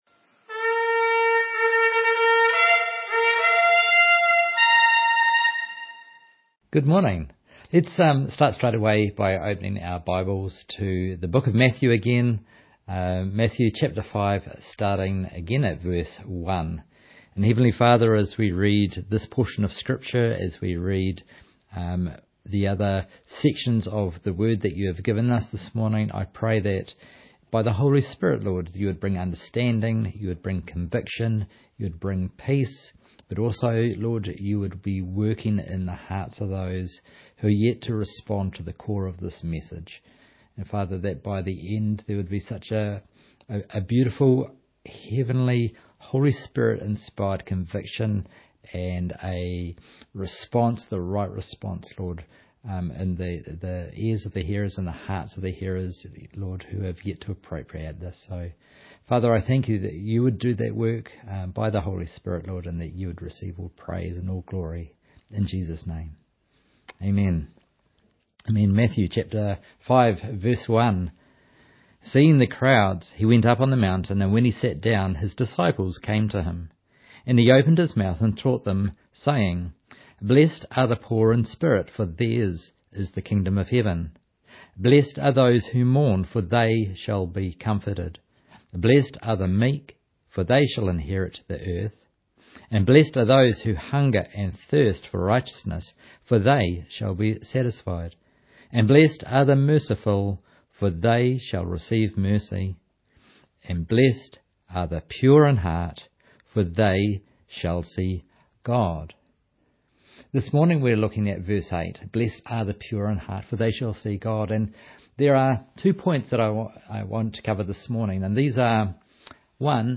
Online Sunday Morning Service 18th May, 2025 Slides from this service are below.